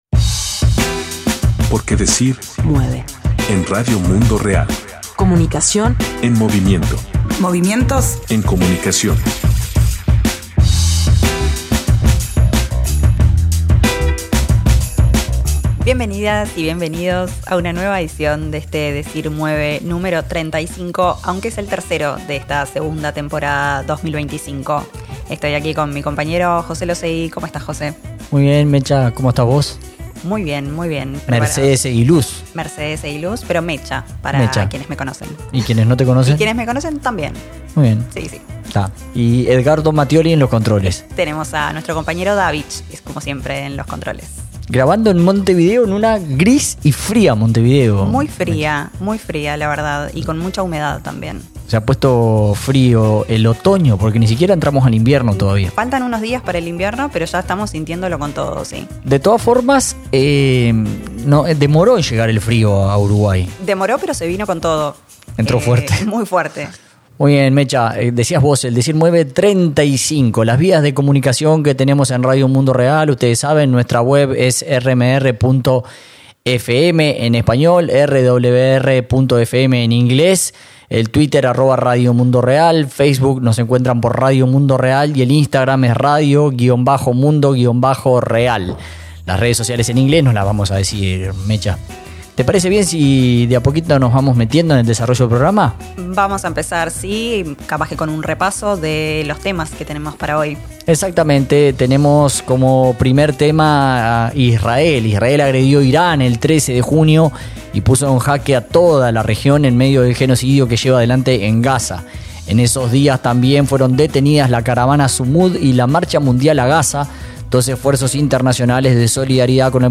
En ese contexto de terror, nuestro recorrido de noticias que hemos dado en llamar “Decir Mueve” repasa, con entrevistas a personas en la región y más allá, la situación en Palestina, el inicio de la agresión israelí a Irán, y la detención de la Caravana Sumoud y la Marcha Mundial a Gaza en Libia y Egipto, dos esfuerzos de solidaridad internacional que buscaban llegar a la Franja.